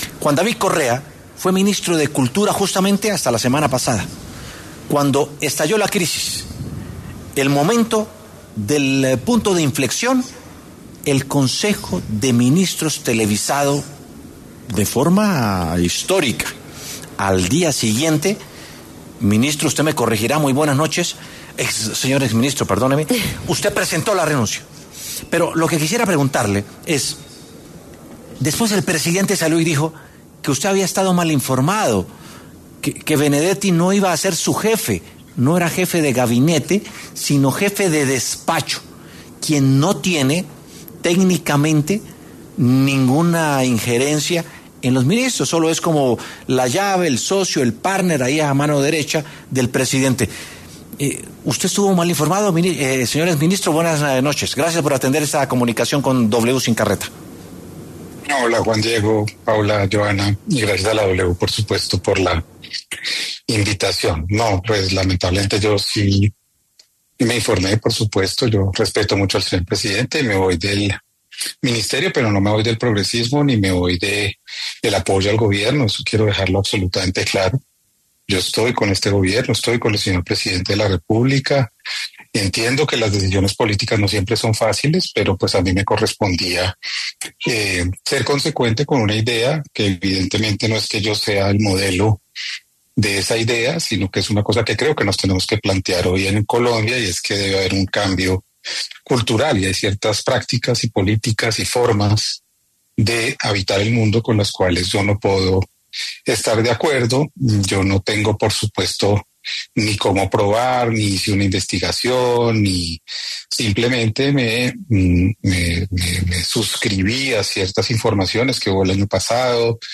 Juan David Correa, exministro de Cultura, explicó en W Sin Carreta que no comparte lo dicho por el presidente Gustavo Petro en el consejo de ministros sobre el feminismo.